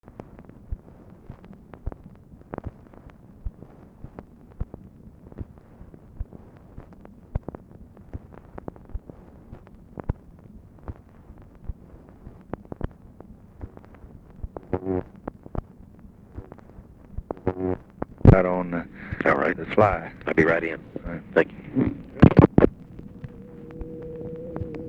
Conversation with WALTER JENKINS, February 3, 1964
Secret White House Tapes | Lyndon B. Johnson Presidency